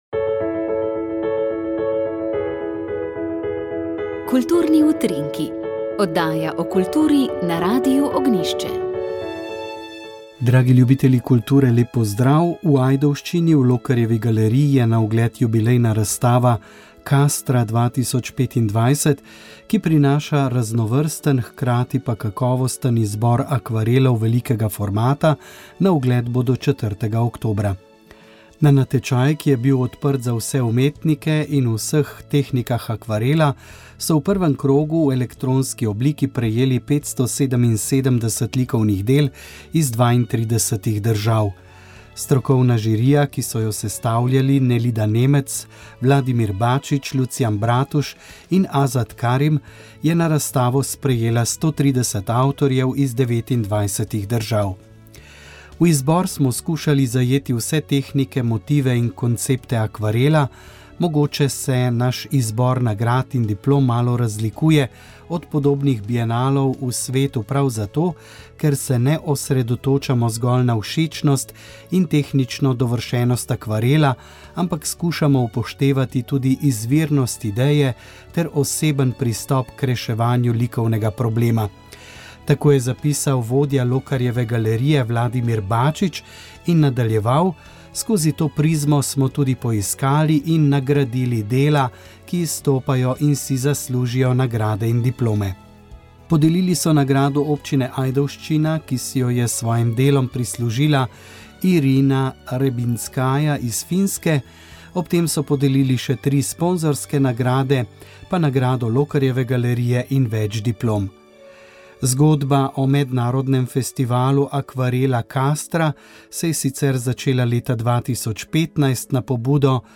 pogovor
Informativni prispevki